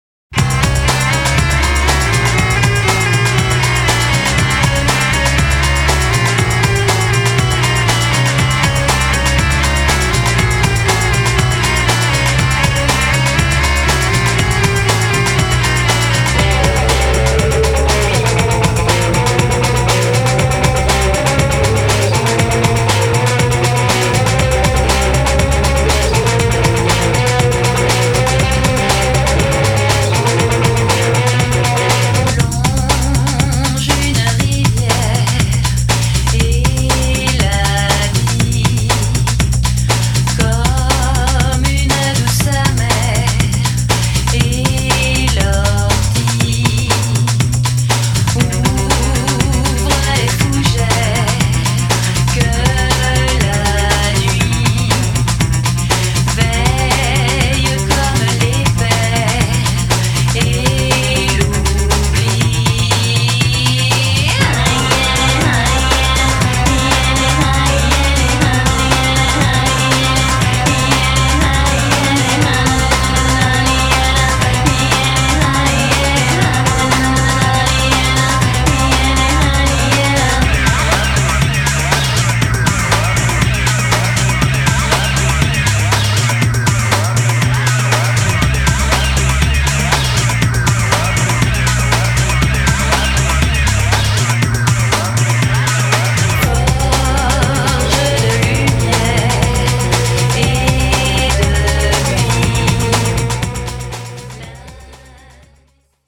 フランスのゴシック、ネオ・クラシカルバンド
エレクトロ・ロック、ニューウェーブファンにオススメです。
voices
cello
keyboards, voices
guitars
bass